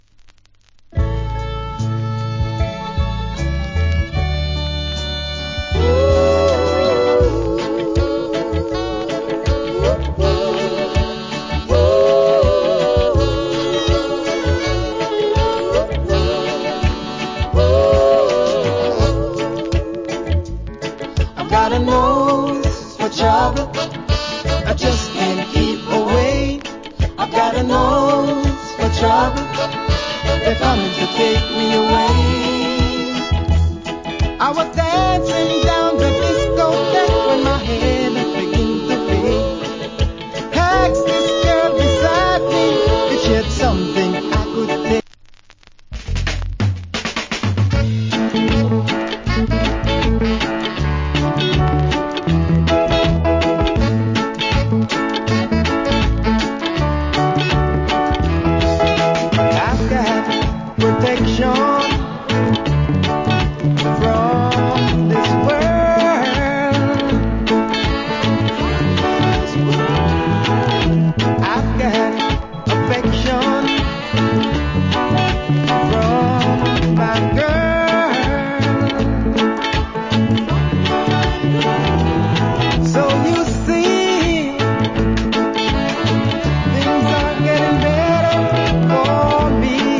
Nice UK Reggae Vocal.